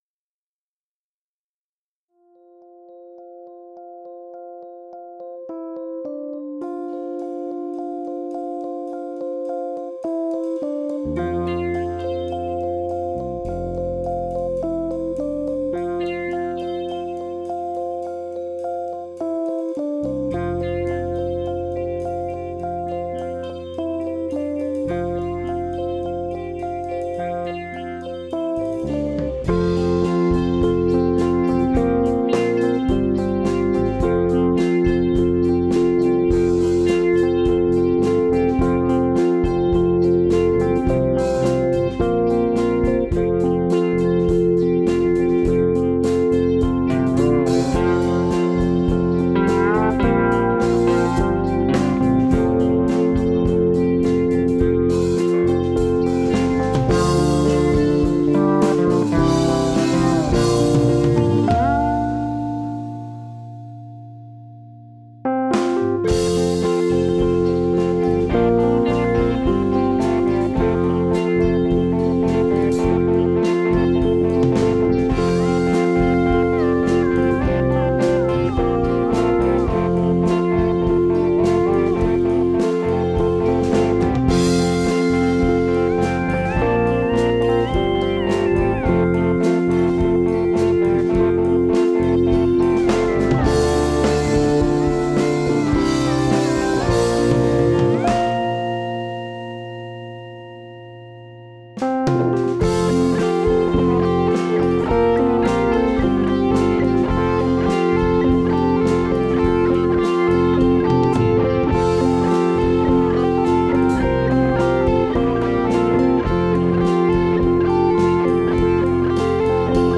An attempt to do something simpler than usual, maybe because we are getting older!
• Electric Guitars
• Sitar simulation: Line 6 Variax 700.
• Bass Guitar: Ibanez SRF700 (fretless).
• Keyboards: Alesis QS8, Nord Stage 2, Arturia Mini Brute.
• Drums: Roland V-Drums triggering the Addictive Drums plug-in, by XLN Audio.
• All effects plug-ins (guitar & bass tones, reverbs, compressors etc.) by Blue Cat Audio.